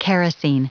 Prononciation du mot kerosene en anglais (fichier audio)
Prononciation du mot : kerosene